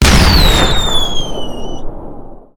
shell.ogg